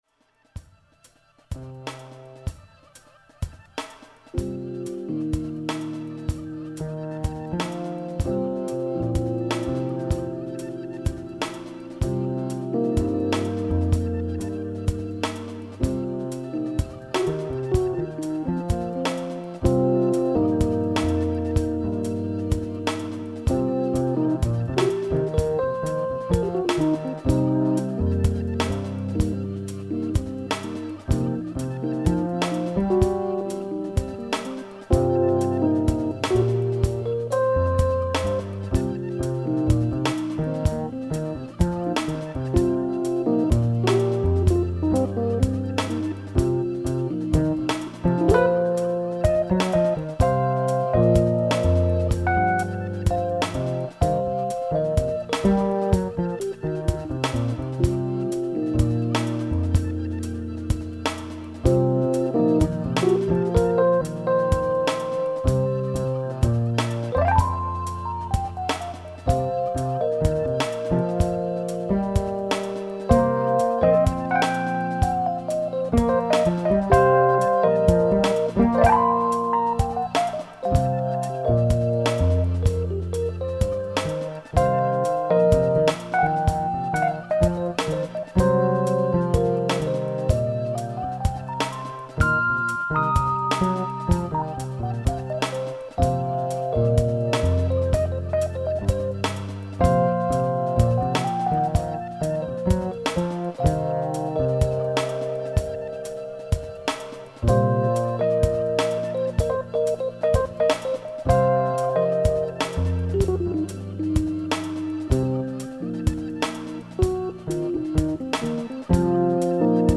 Tempo: 65bpm / Date:12.01.2018